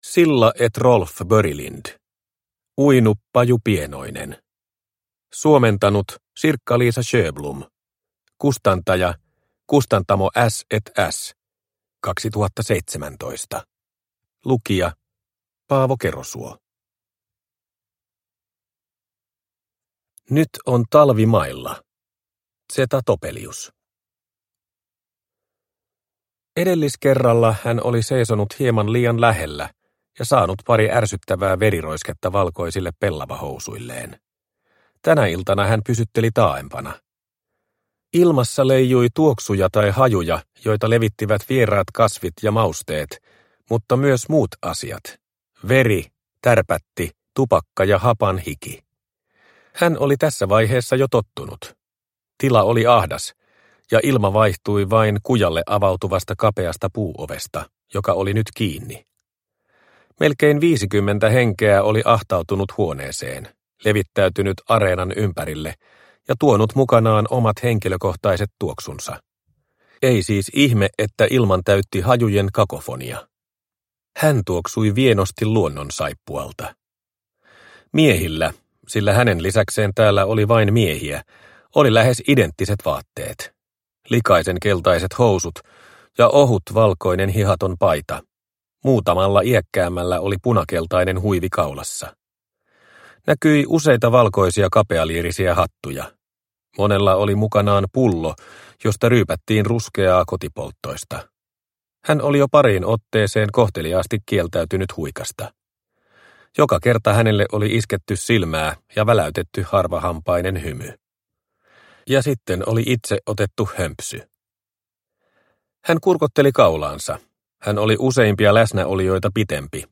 Uinu, paju pienoinen – Ljudbok – Laddas ner